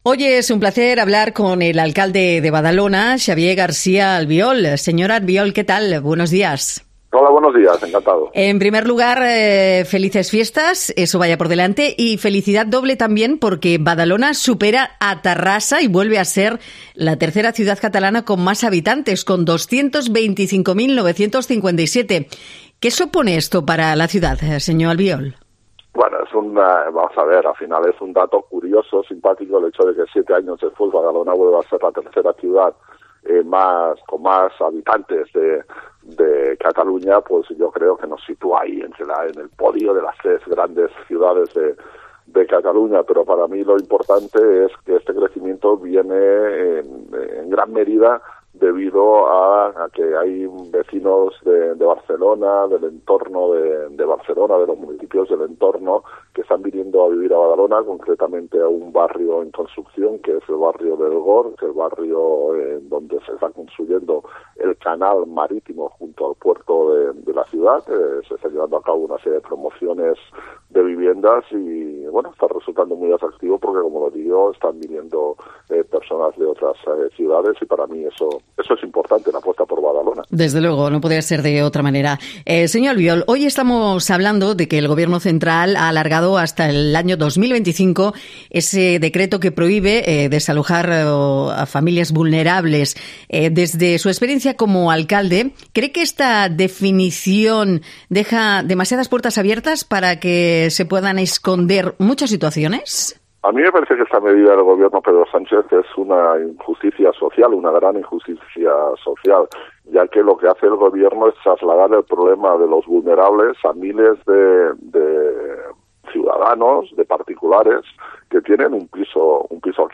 Xavier Garcia Albiol para a Herrera en COPE sobre el problema de Badalona respecte a la vivenda social